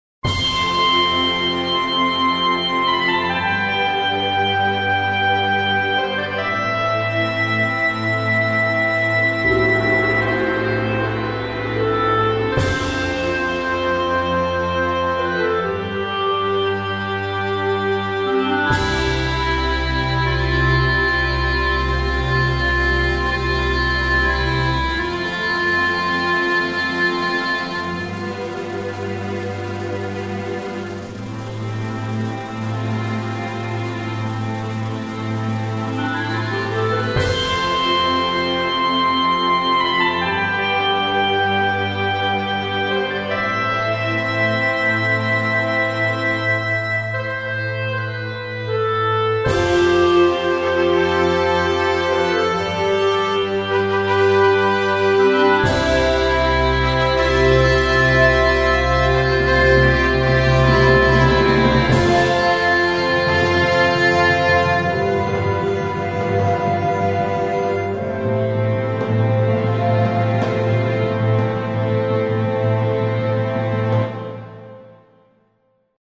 Музыкальный подклад к документально-историческим программам.